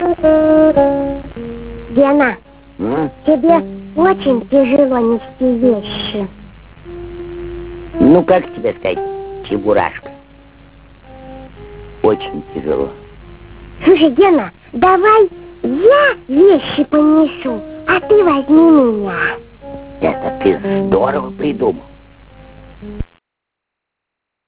HERE to hear a sound file of Gena and Cheburashka performing this scene.
suitcase.ram